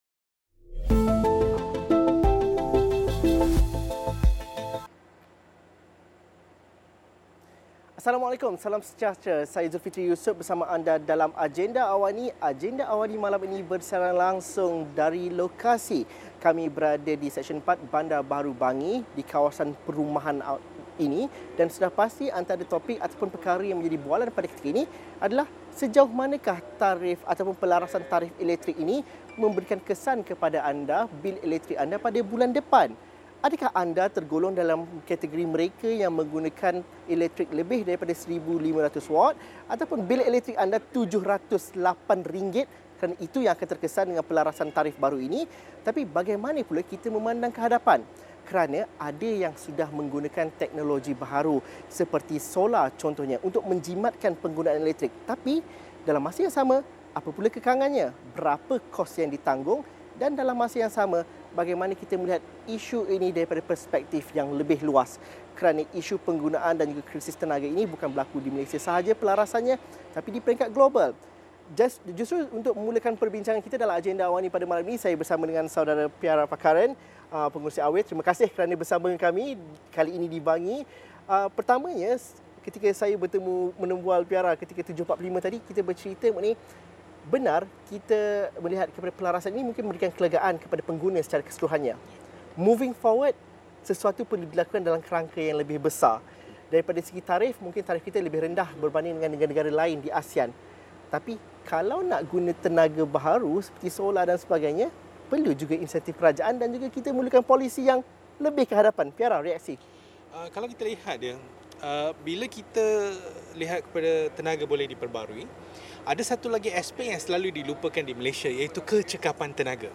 Pelarasan tarif elektrik baharu, kelompok mana yang akan terkesan? Penggunaan tenaga solar di kediaman domestik sebagai alternatif, adakah berbaloi? Siaran Langsung Agenda AWANI dari Bandar Baru Bangi bermula 7.45 malam.